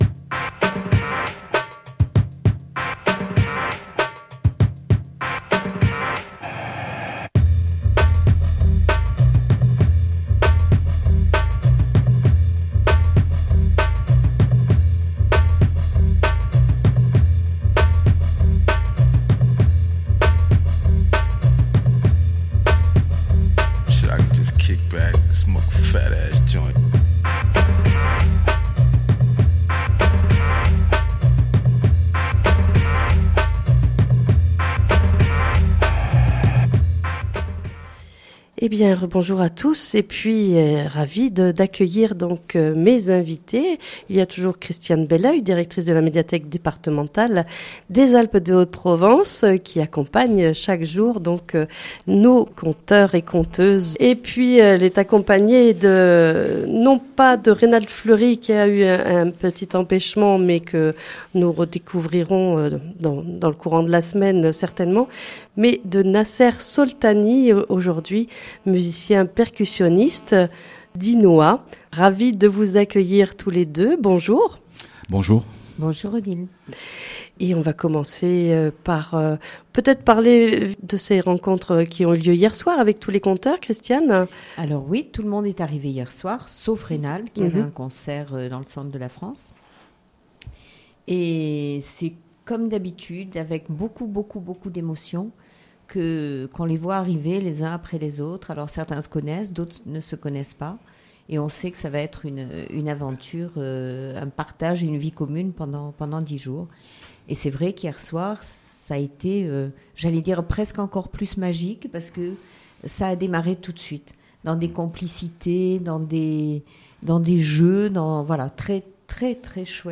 Rencontre avec ce musicien dont le partage et la communication avec les autres sont les maîtres mots de sa vie.